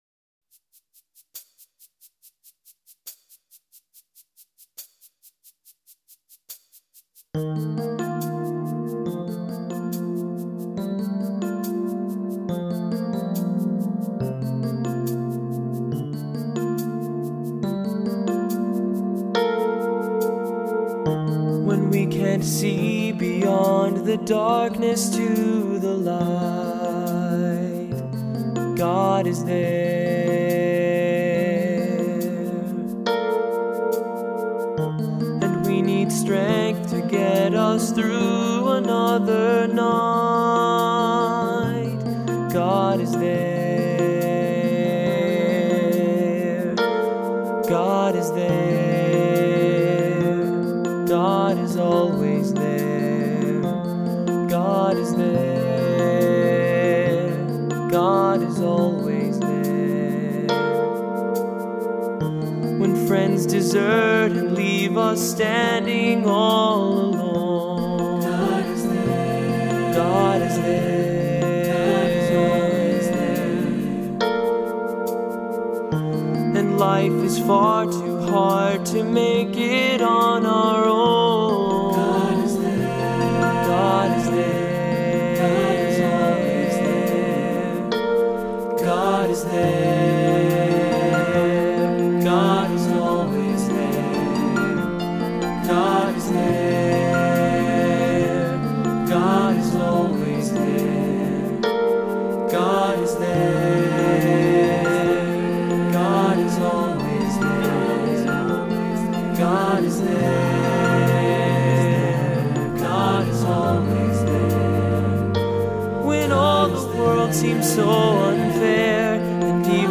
Music Category:      Christian
Refrain may be used as ostinato. For cantor or soloist.